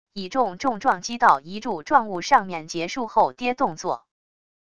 以重重撞击到一柱状物上面结束后跌动作wav音频